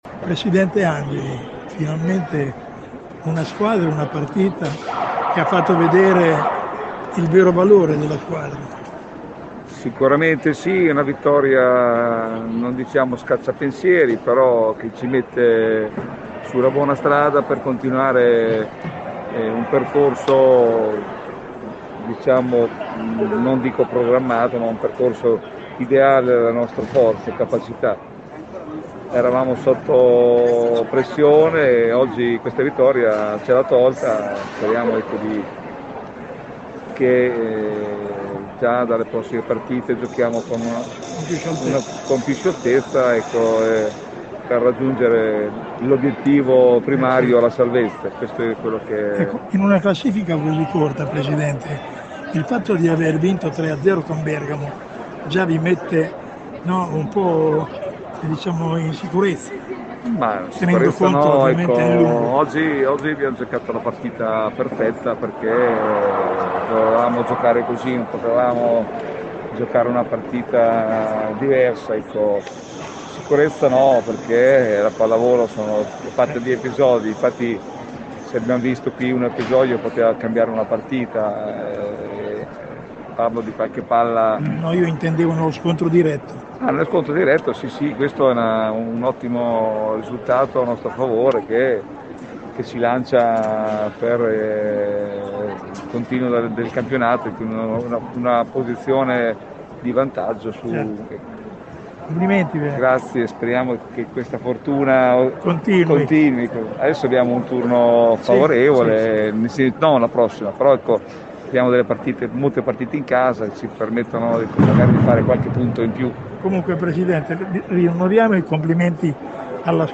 La Megabox Ondulati Del Savio Vallefoglia vince in tre set una partita importantissima contro Bergamo, avversaria diretta nella lotta per la salvezza. Le interviste del post partita